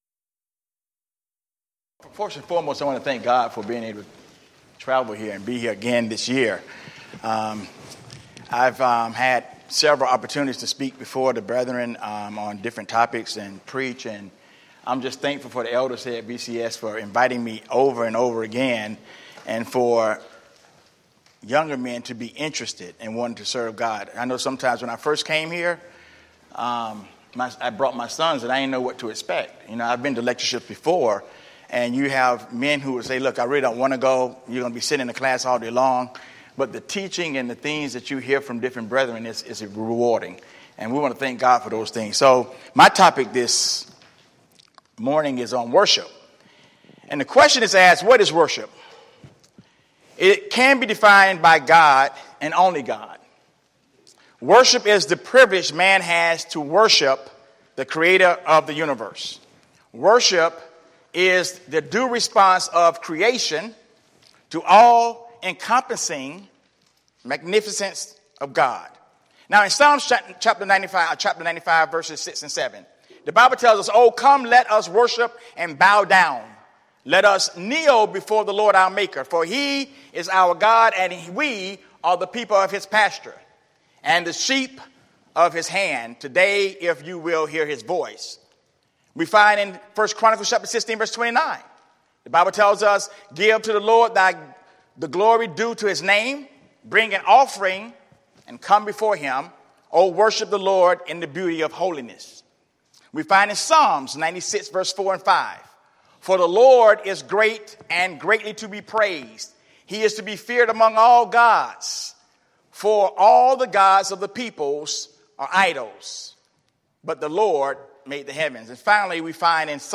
Event: 5th Annual Men's Development Conference Theme/Title: Repent & Be Converted
lecture